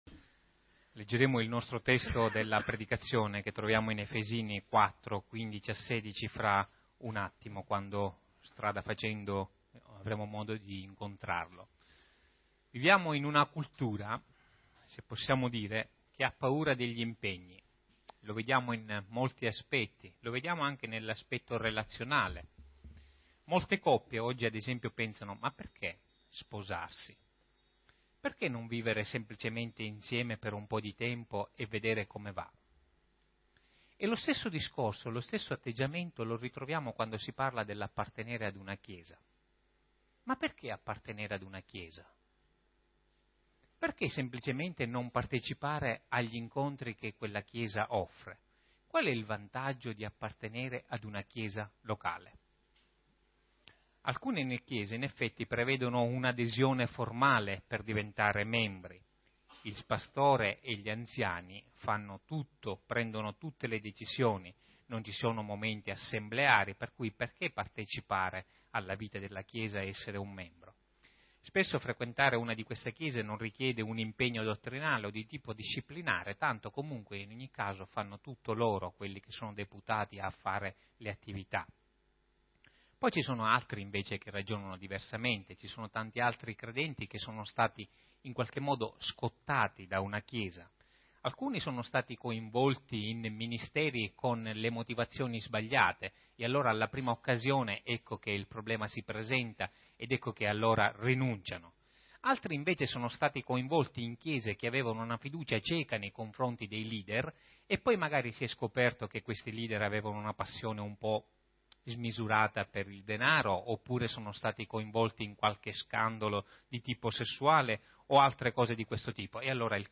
Home » Predicazioni » Strumenti di grazia e segni di discepolato » Perché appartenere ad una chiesa? Efesini 4:15-16 e altri testi